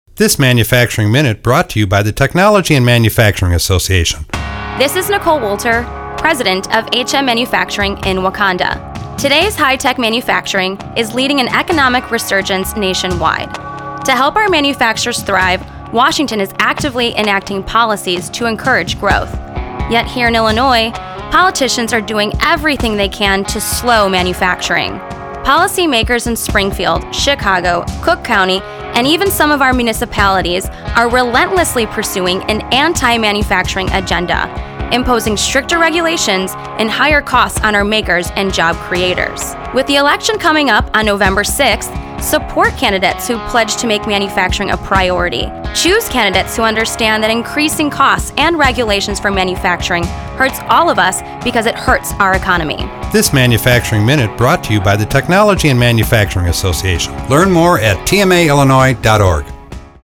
TMA releases first Manufacturing Minute radio ad